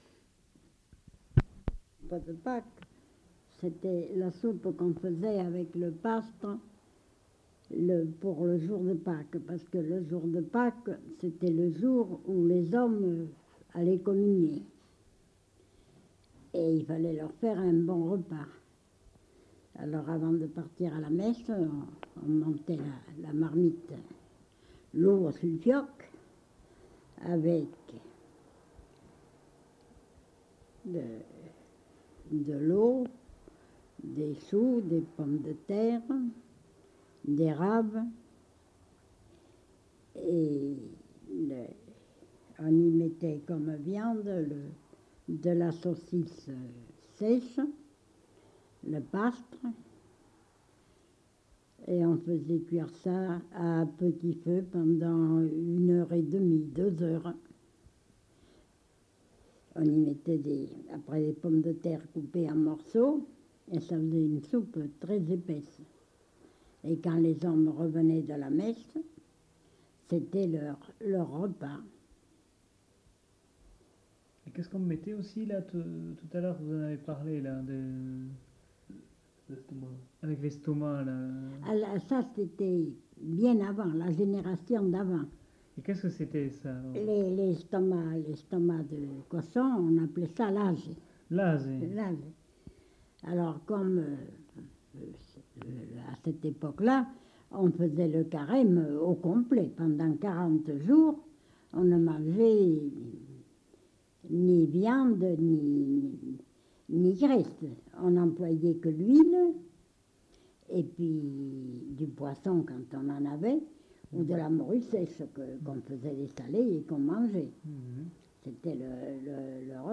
Lieu : Tournay
Genre : témoignage thématique